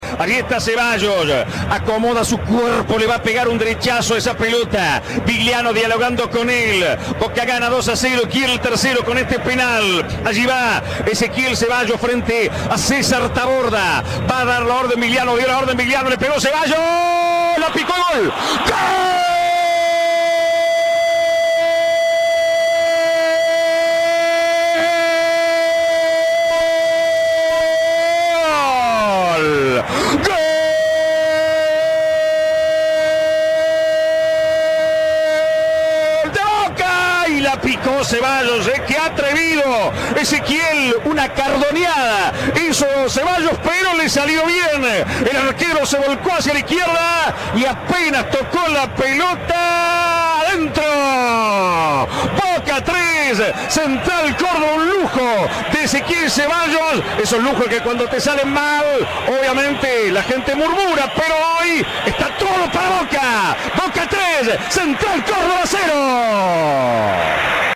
Fiesta en "La Bombonera": reviví el relato de los 8 goles